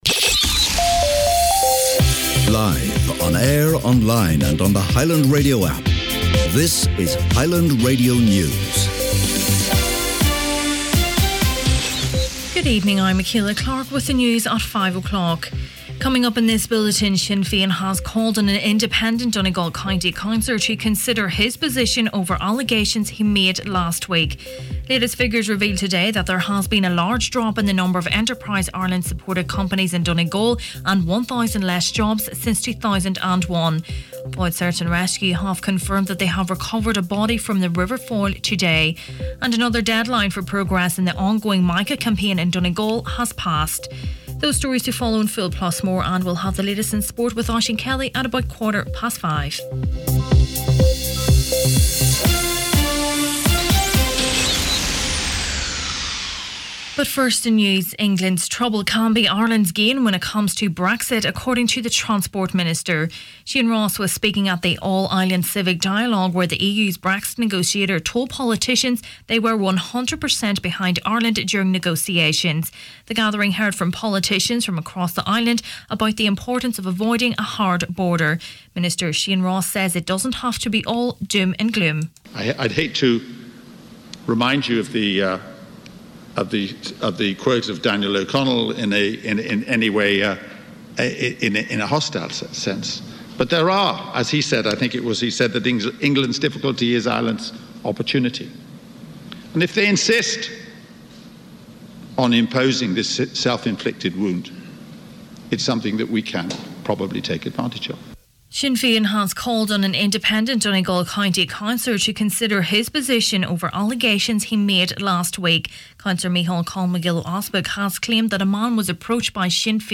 Main Evening News, Sport and Obituaries Monday 30th April